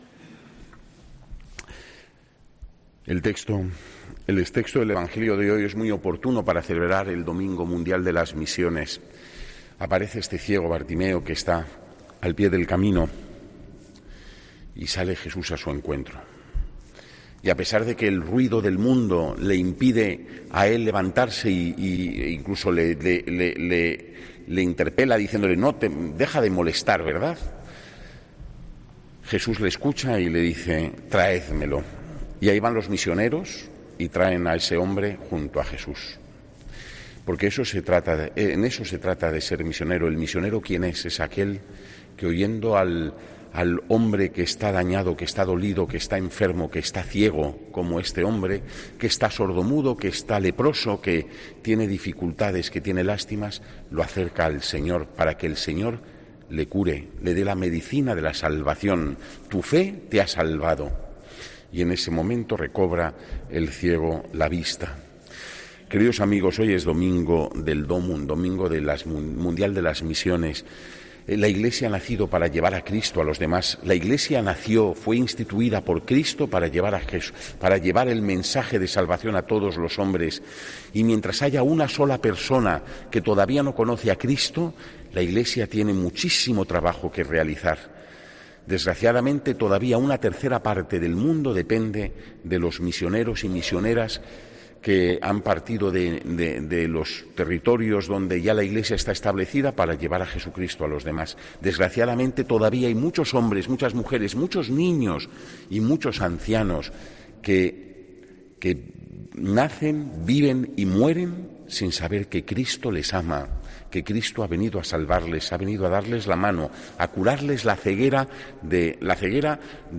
HOMILÍA 24 OCTUBRE 2021